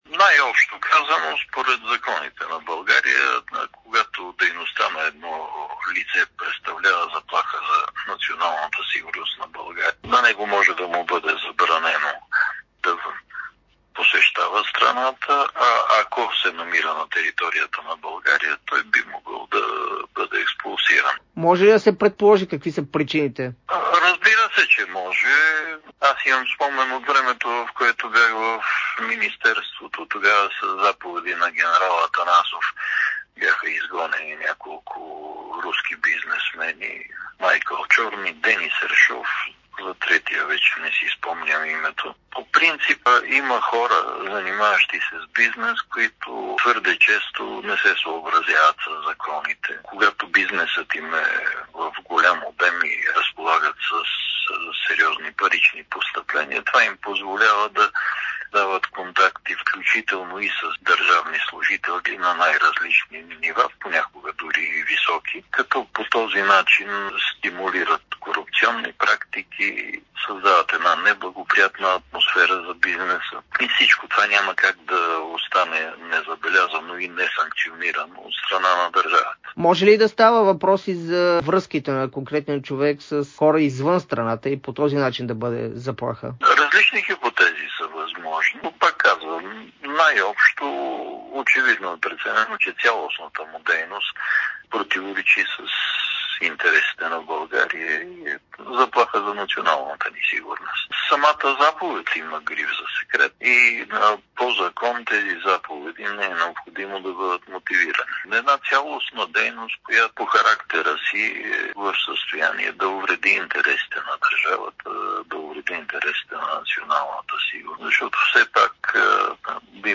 Адвокатът и бивш министър на вътрешните работи Емануил Йорданов даде ексклузивно интервю пред Дарик и dsport, в което обясни какви биха могли да бъдат... (12.11.2024 16:42:06)